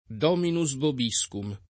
Dominus vobiscum
[lat. d 0 minu @ vob &S kum ]